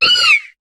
Cri de Skitty dans Pokémon HOME.